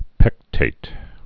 (pĕktāt)